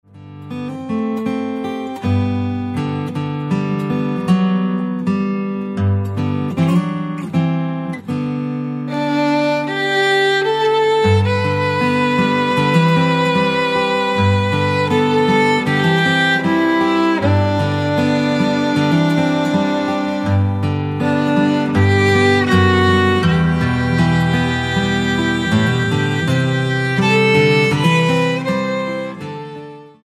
Traditional hymn instrumentals for guitar, violin and flute